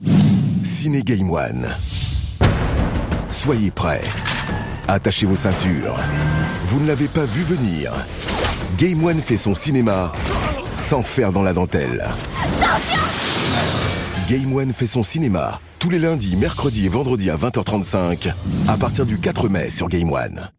VOIX OFF, animateur radio
Sprechprobe: Werbung (Muttersprache):